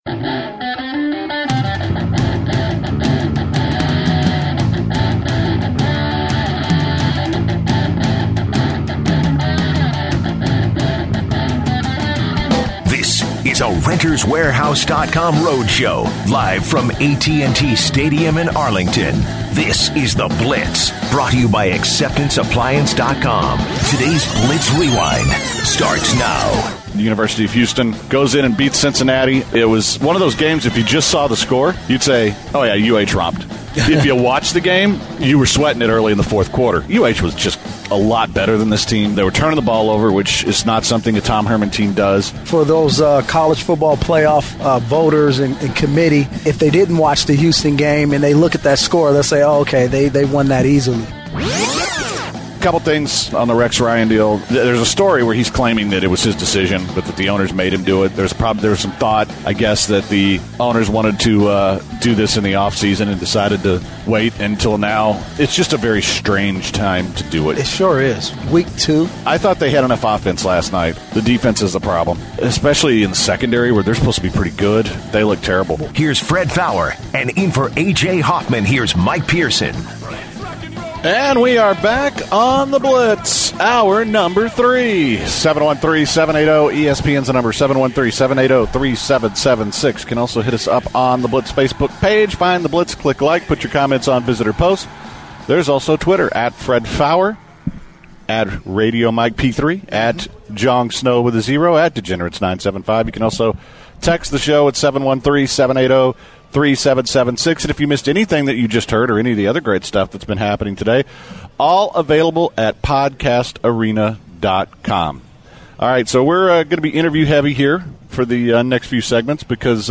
They start the show by interview Andre Ward’s opponent Sergey Kovalev, also known as “The Crusher”.
The Hosts then talk to Roy Jones Jr. and he talks about who he thinks will win and how the fight will go down. They ask Roy Jones Jr. his thoughts on the Kaepernick situation.